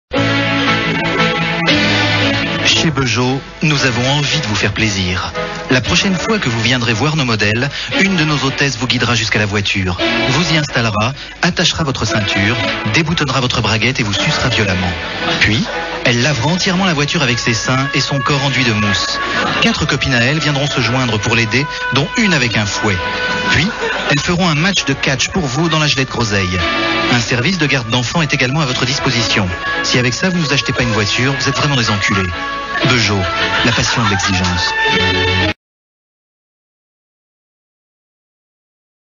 Beugeot : la voiture, le désir, la vendeuse – pub imaginée par les Nuls en 1994 sur Europe 1 Zouzouk – Le grenier de la FM